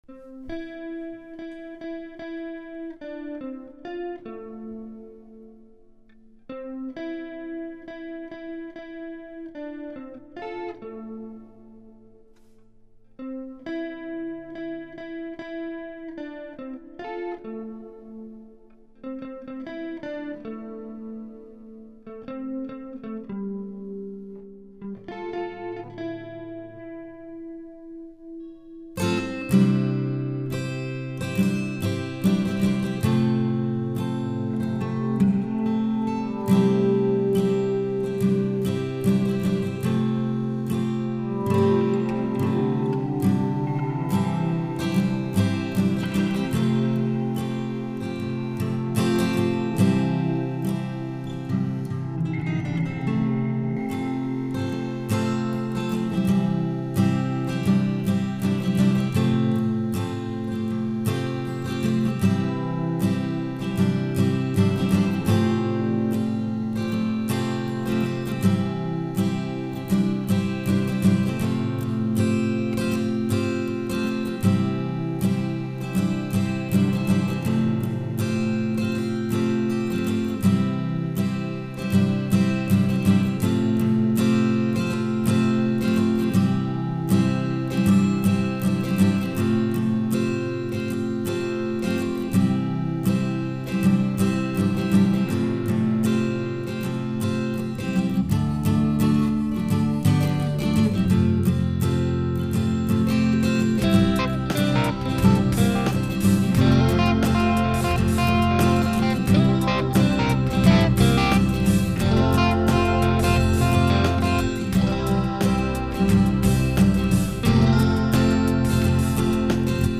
I added detune effect to it to make it out.
ACOUSTIC GUITAR
French horn